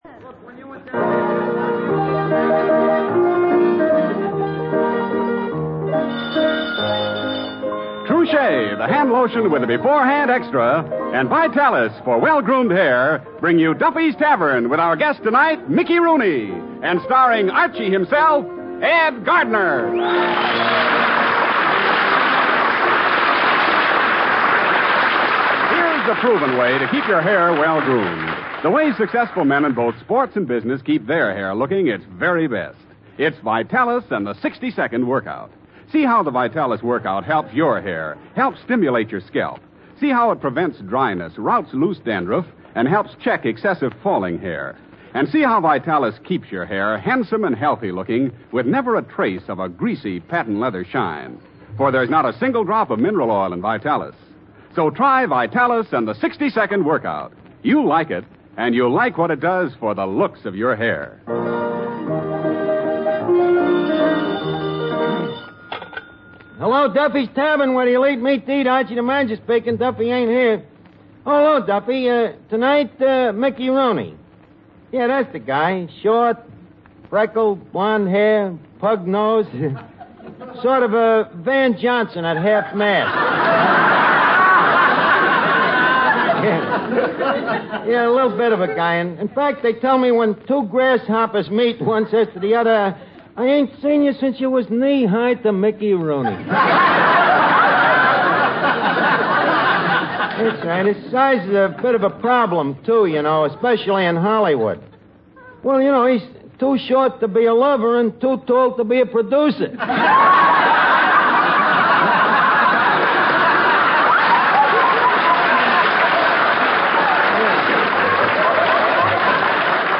Duffy's Tavern Radio Program, Starring Ed Gardner